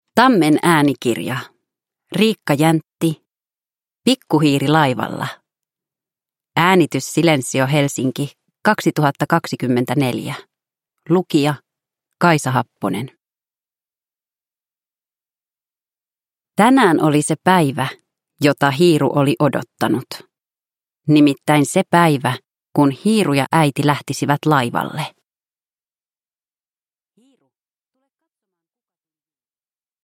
Pikku hiiri laivalla – Ljudbok